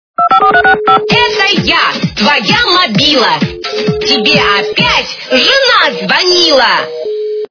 » Звуки » Люди фразы » Голос - Этo я мабилa! Teбe жeнa звoнилa
При прослушивании Голос - Этo я мабилa! Teбe жeнa звoнилa качество понижено и присутствуют гудки.